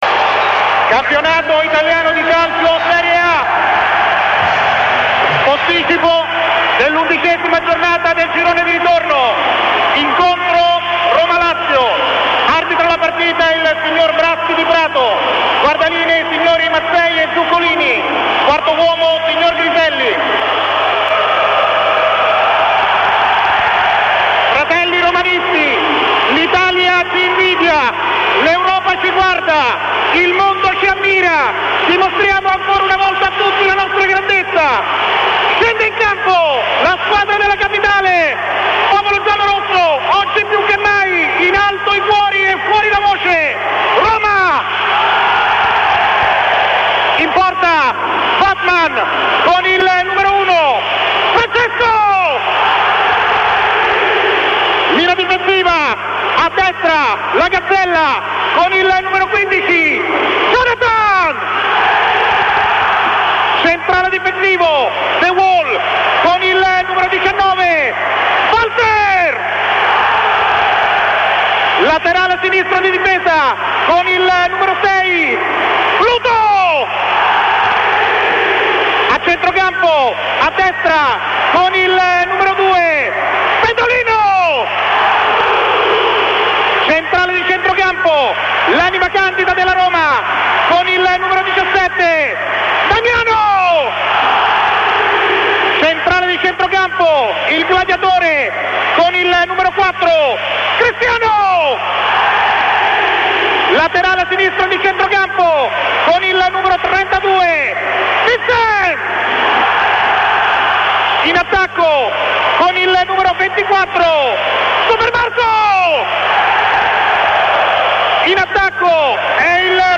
formazione della magica dalla viva voce